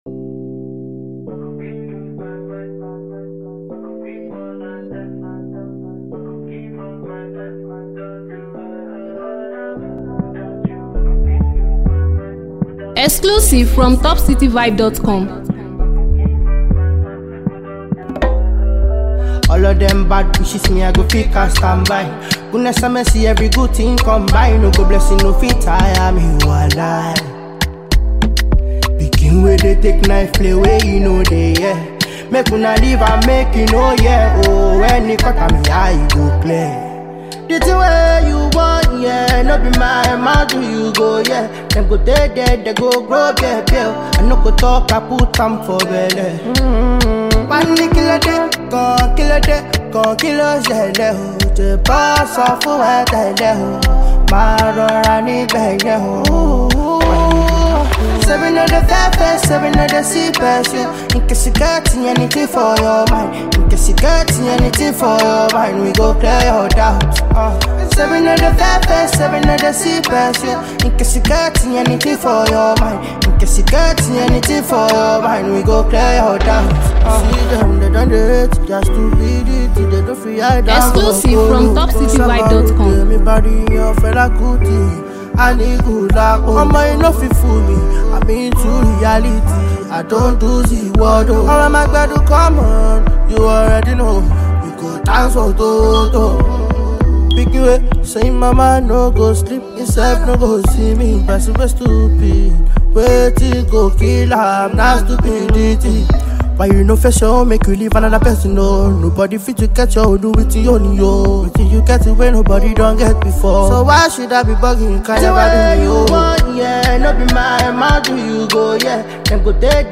Similarly, you shouldn’t pass up this amazing pop tune.
guitar